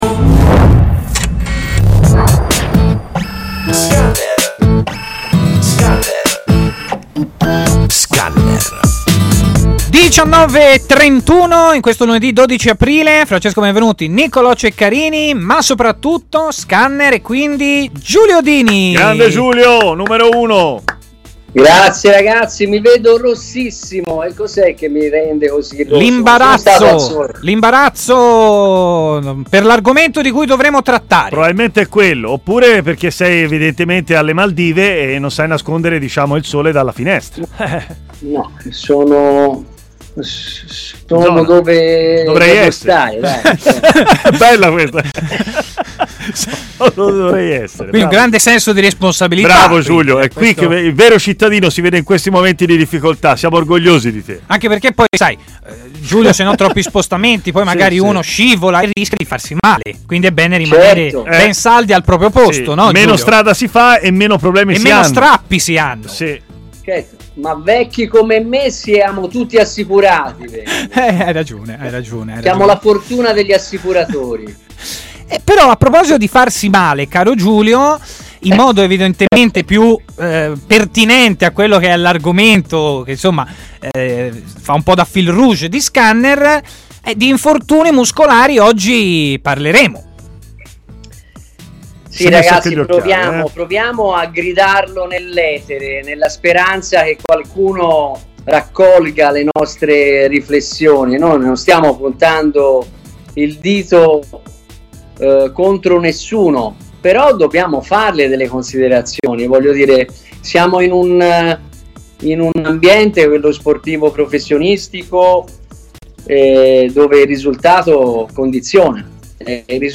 intervenuto su TMW Radio, nel corso della trasmissione Scanner, per analizzare la qualità atletica nel calcio professionistico italiano
L'intervento completo nel podcast!